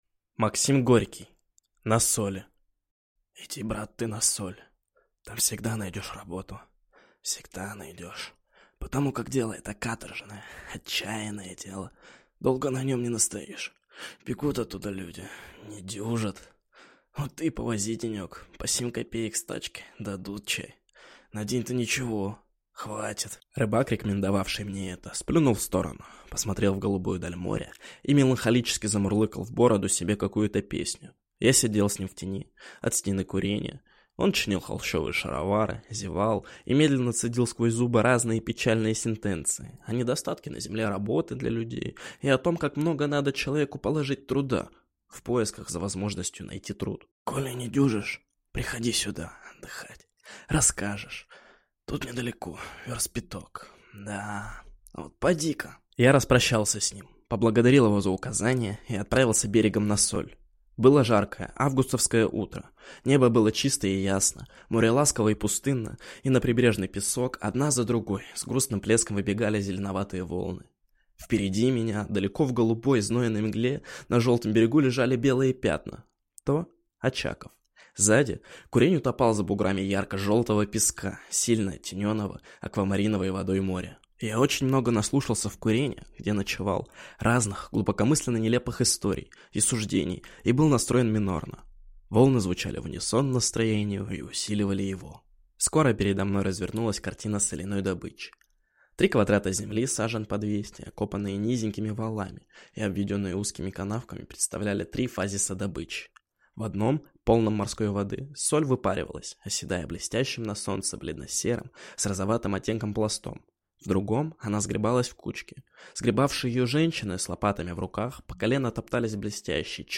Аудиокнига «На соли».